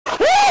TOECAR Scream